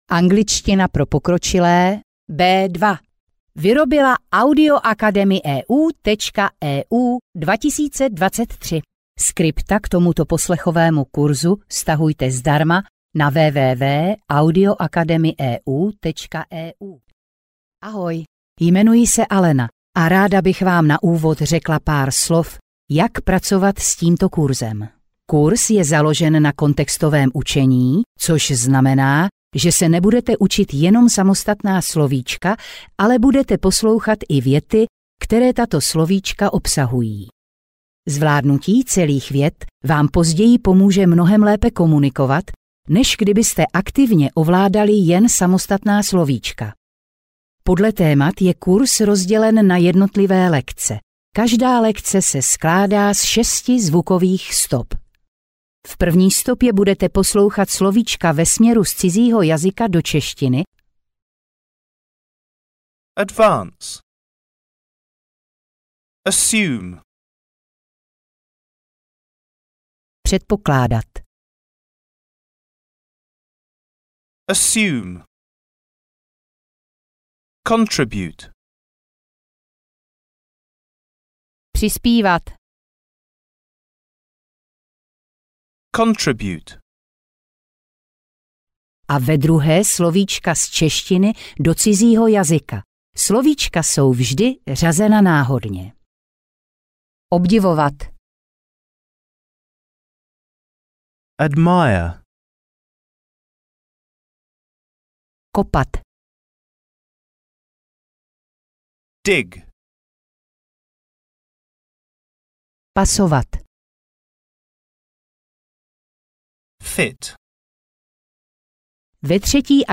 Angličtina pro středně pokročilé B2 audiokniha
Ukázka z knihy
Dále máte k dispozici slovíčko následované příkladovou větou, opět v obou variantách překladu (stopa 3 a 4).